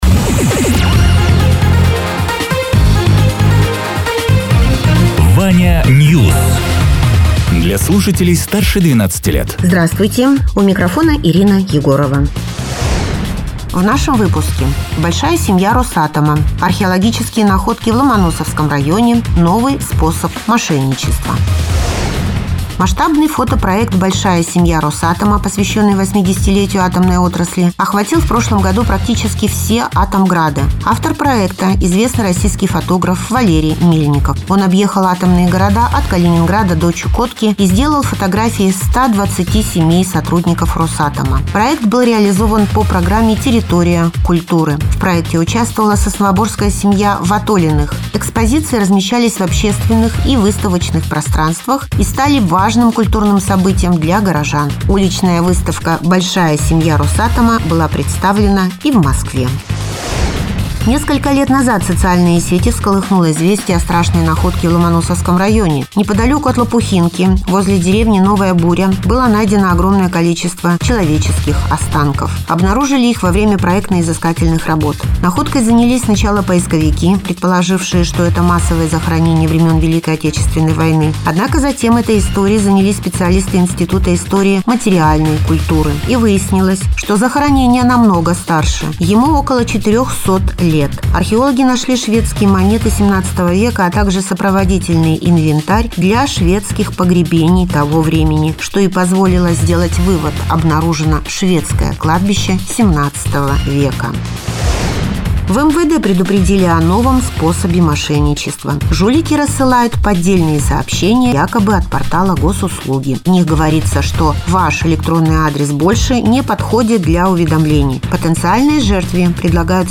Радио ТЕРА 04.02.2026_10.00_Новости_Соснового_Бора